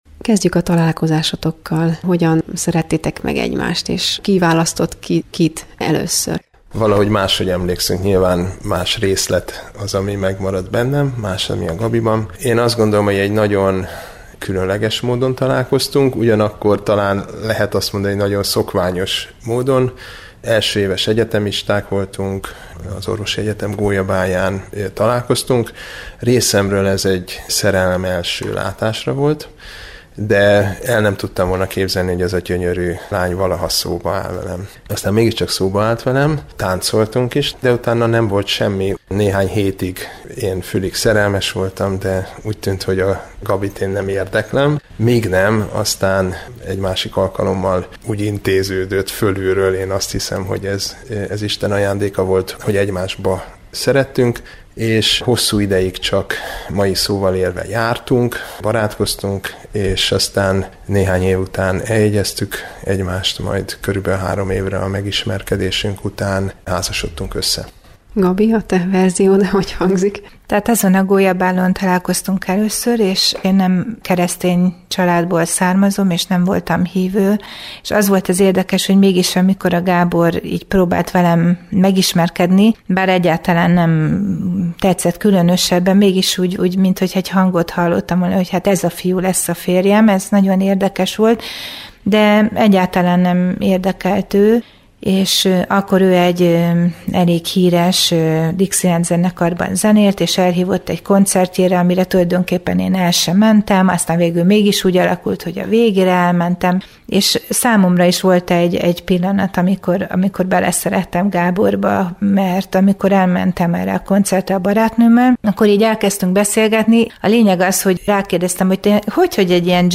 Hogy hogyan találkoztak és lettek egy pár, és miként élik meg a nagycsalád hétköznapjait, megtudhatják a házaspárral készült interjúból.